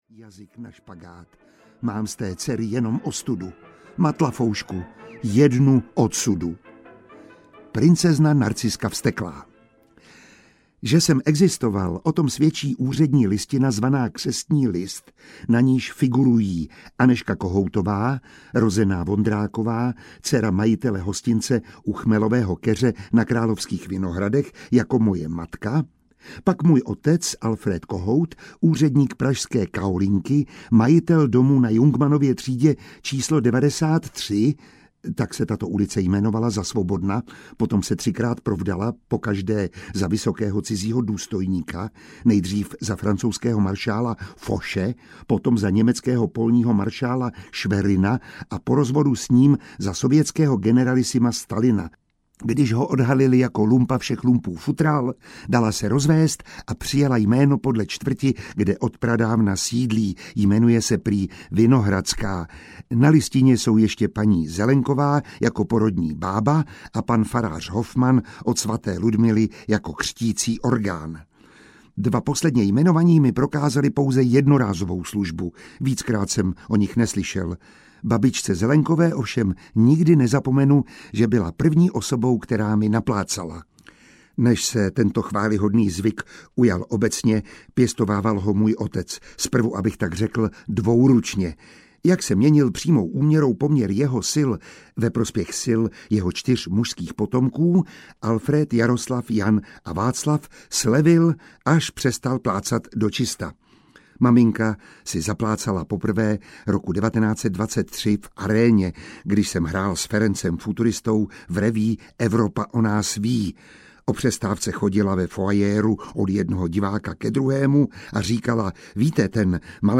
HOP SEM, HOP TAM audiokniha
Ukázka z knihy
• InterpretMiroslav Táborský